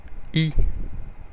ÄäntäminenFrance (Paris):
• IPA: /i/